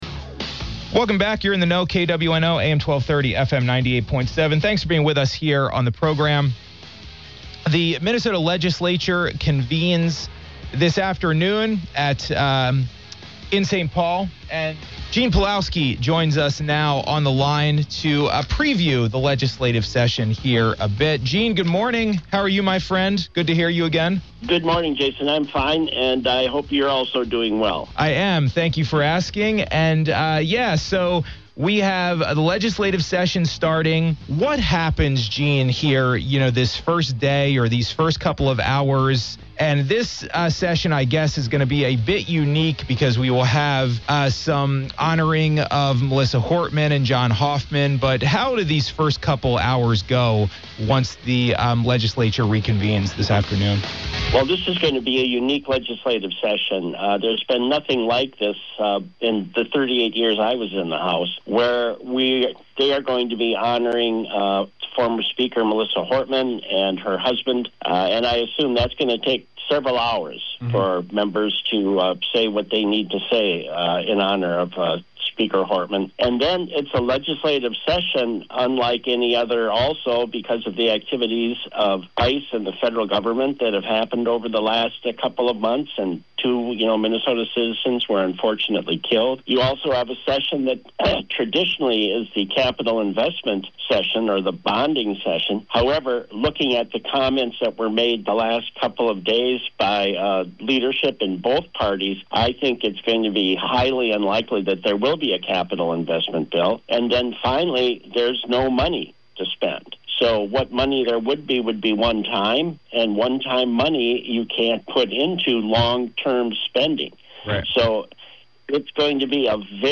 (KWNO)-Gene Pelowski previews the 2026 Minnesota legislative session. Listen to our conversation with the former state representative from Winona.